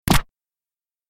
دانلود آهنگ تصادف 33 از افکت صوتی حمل و نقل
دانلود صدای تصادف 33 از ساعد نیوز با لینک مستقیم و کیفیت بالا
جلوه های صوتی